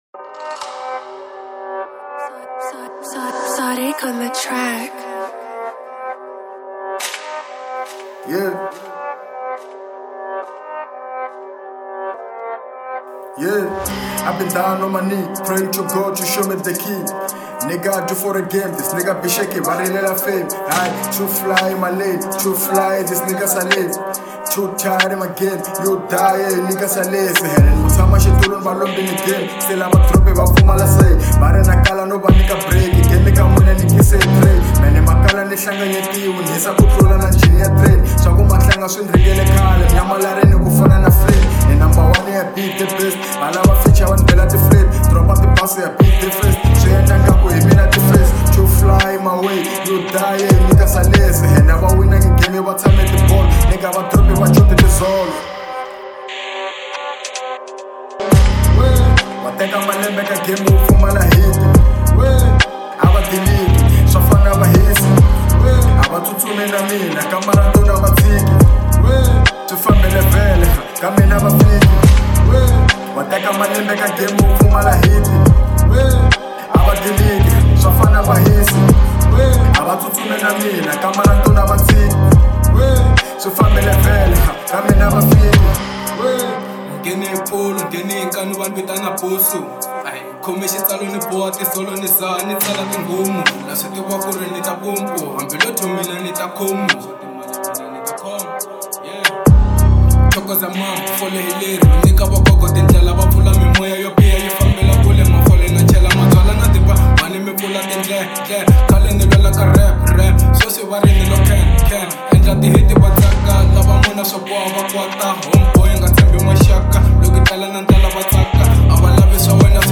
03:22 Genre : Hip Hop Size